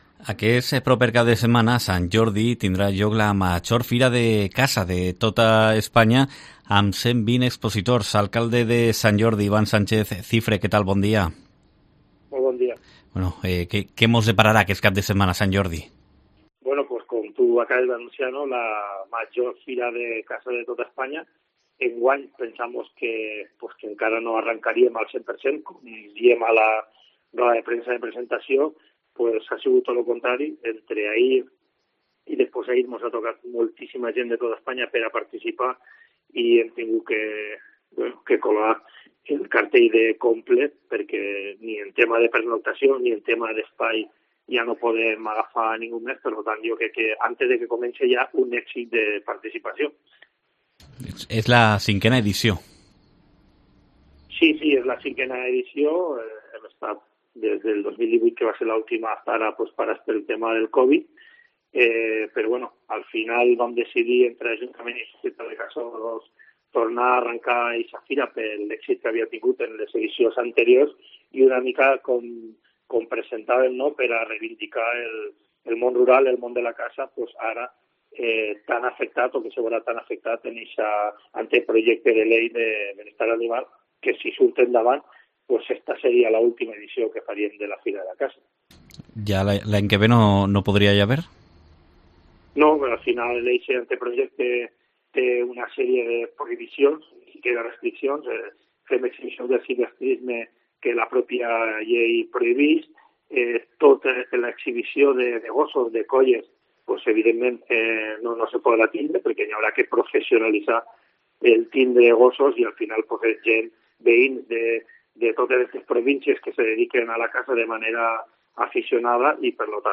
Entrevista
Sant Jordi celebra la V Feria de la Caza con 120 expositores, como explica en COPE el alcalde, Iván Sánchez Cifre